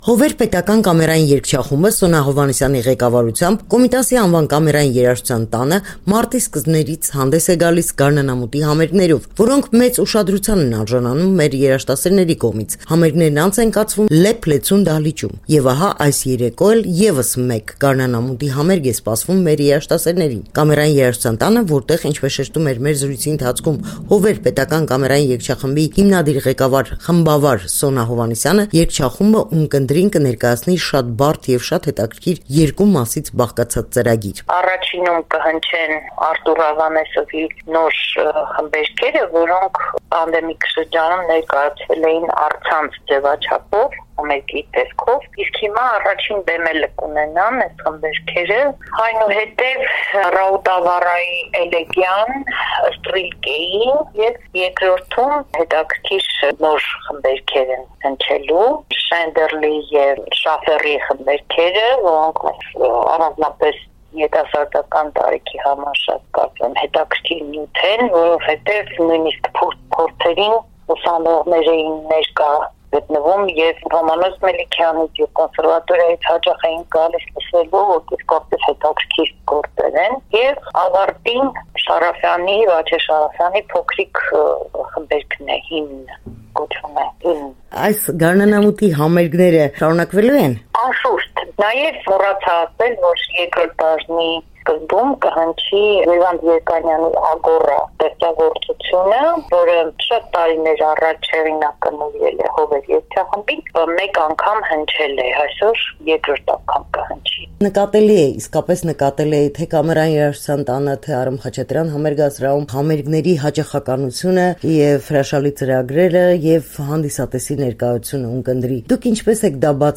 Գարնանամուտի համերգներ՝ «Հովեր» երգչախումբի կատարմամբ